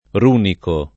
runico [ r 2 niko ] agg.; pl. m. ‑ci